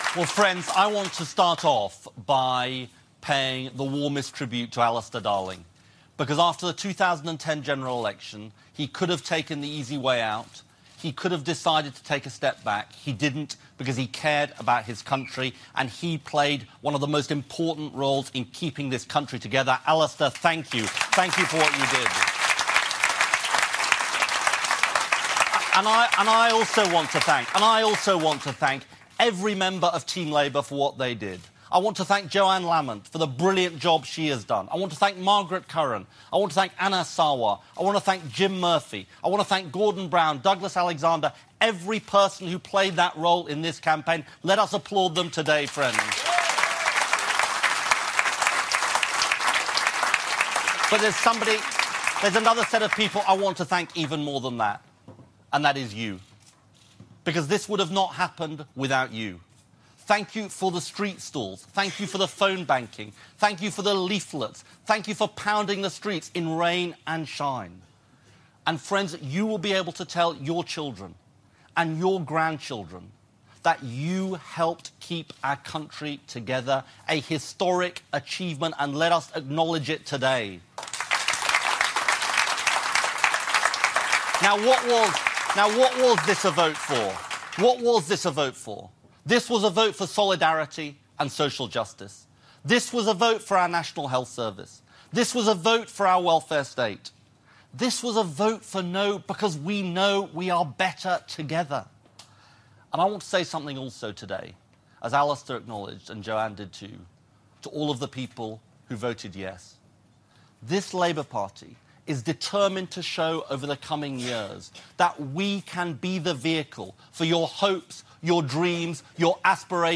Ed Miliband's statement on the referendum